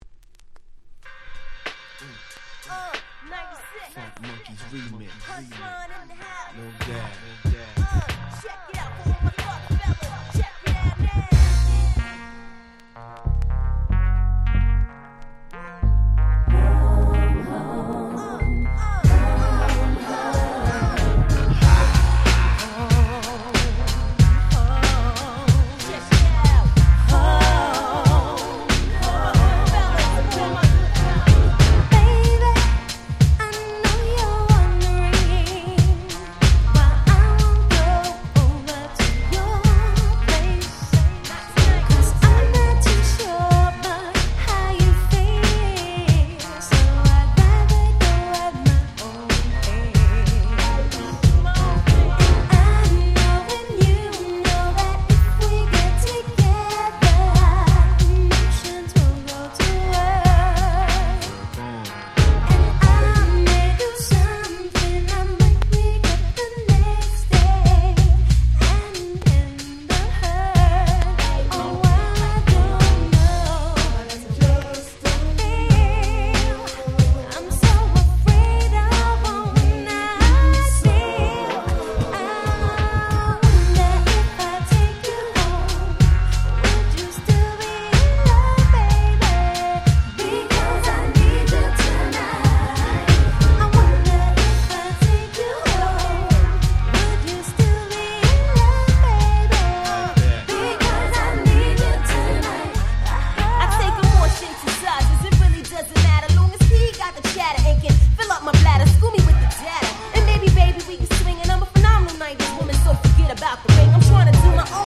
96' Very Nice R&B / New Jack Swing / Hip Hop Soul !!
90's NJS ニュージャックスウィング ハネ系